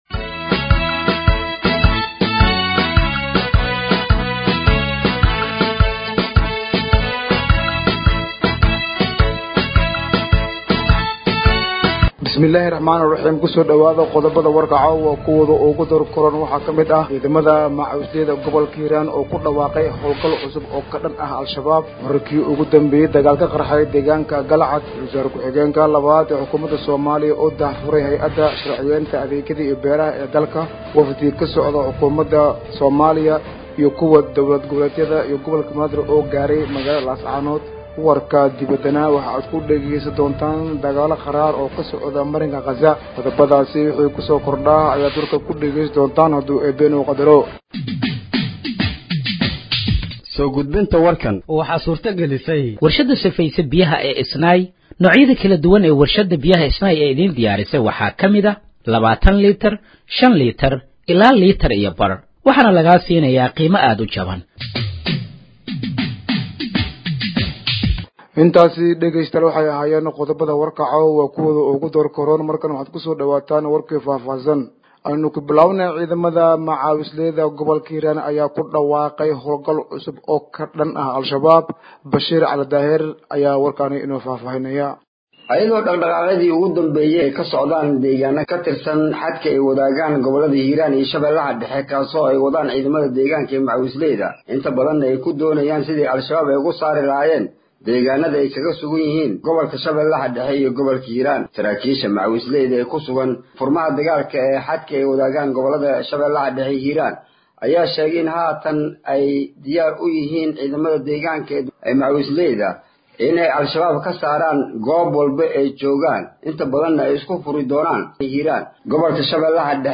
Dhageeyso Warka Habeenimo ee Radiojowhar 25/05/2025